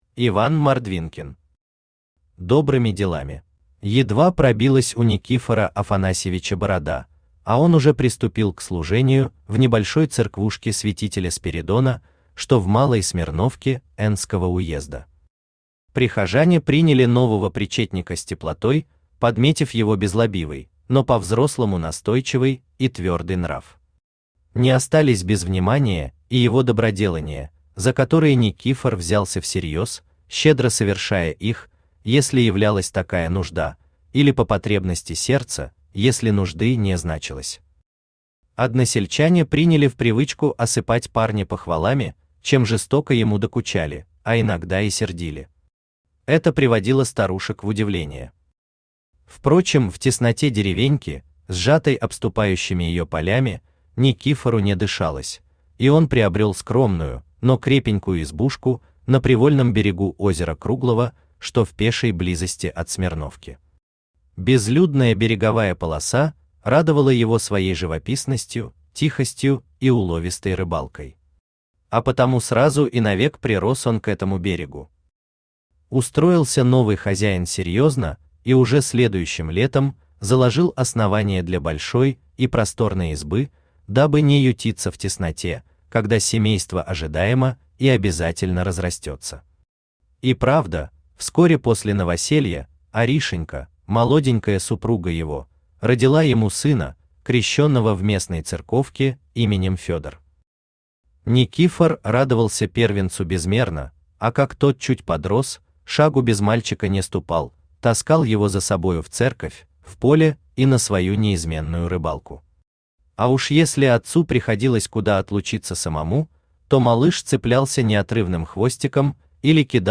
Аудиокнига Добрыми делами | Библиотека аудиокниг
Aудиокнига Добрыми делами Автор Иван Александрович Мордвинкин Читает аудиокнигу Авточтец ЛитРес.